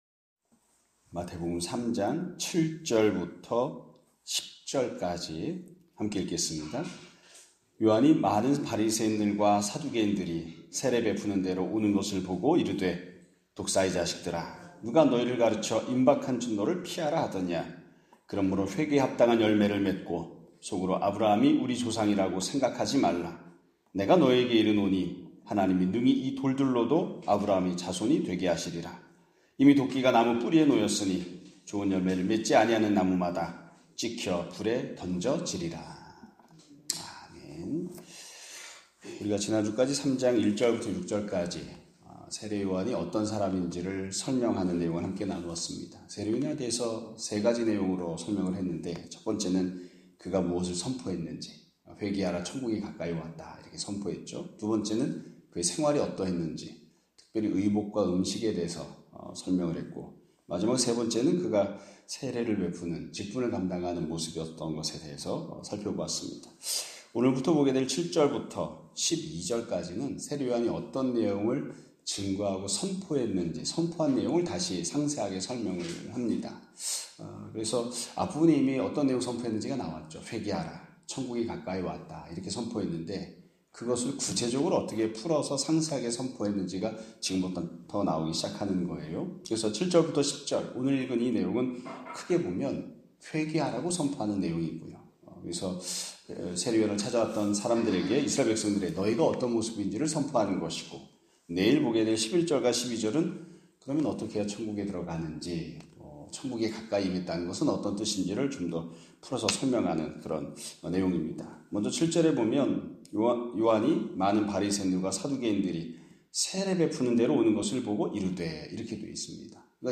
2025년 4월 14일(월요일) <아침예배> 설교입니다.